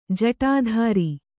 ♪ jaṭadhāri